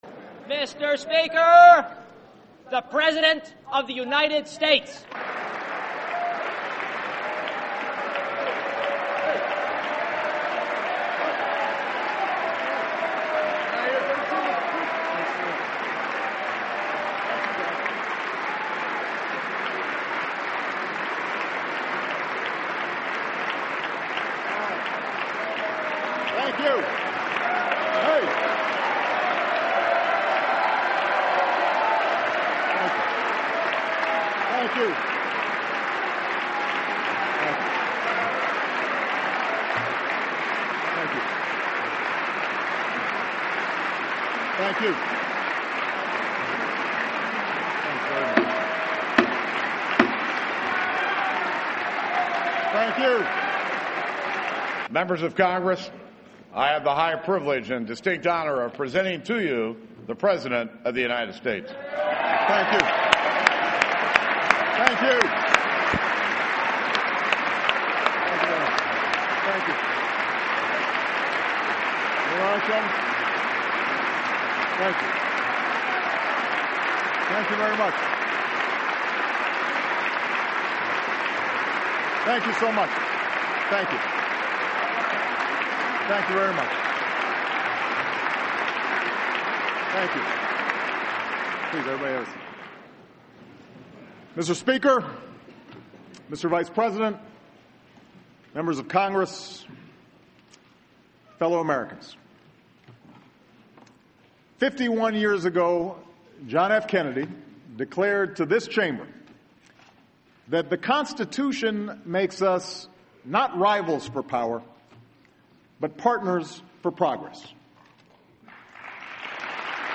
President Barack Obamas State Of The Union Address - Tuesday 12 February 2013